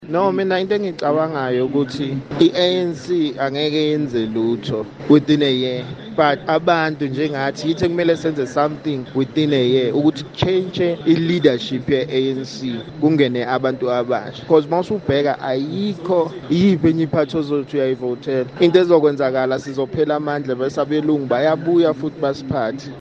Kaya Drive with Sizwe Dhlomo asked listeners if there was anything the ruling party could do to win back votes.